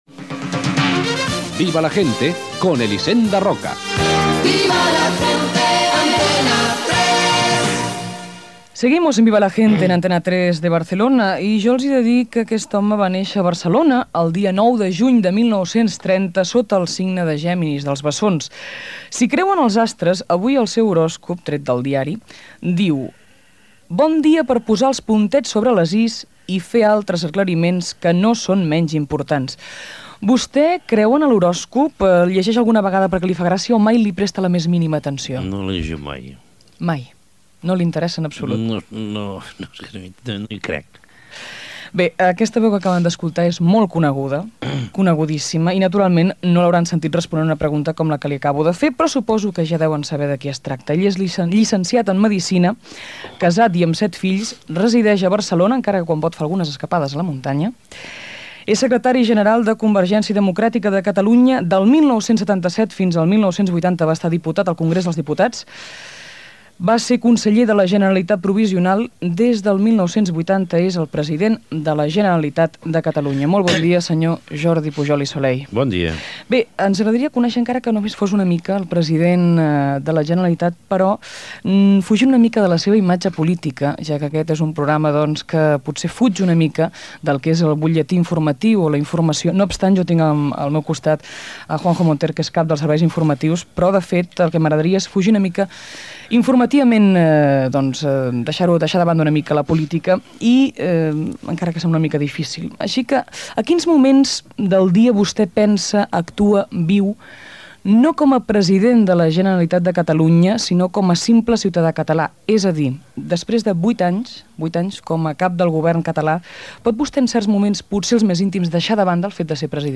Indicatiu del programa,presentació i entrevista al president de la Generalitat Jordi Pujol. S'hi parla de com passa els caps de setmana, de la seva família, de la natalitat a Catalunya
Entreteniment